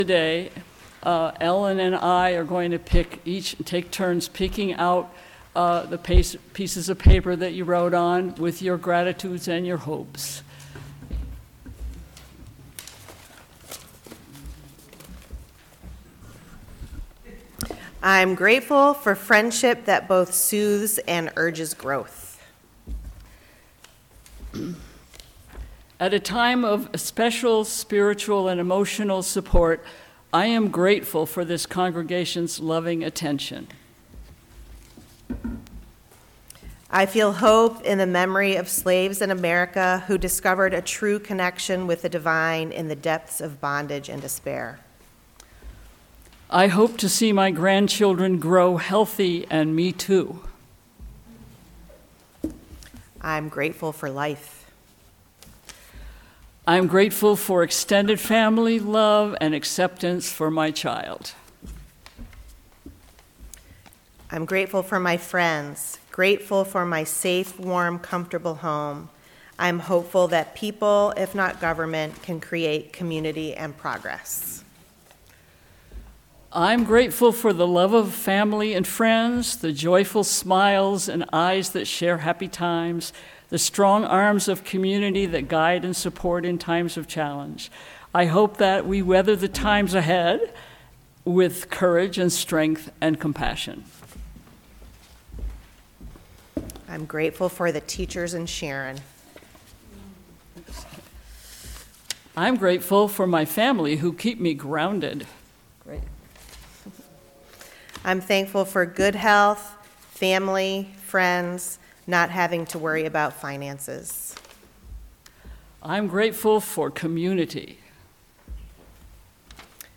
Our chalice circles follow the usual format of our worship services, with time for reflection and sharing from those present instead of a full sermon. Lay-led service.